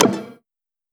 toolbar-select.wav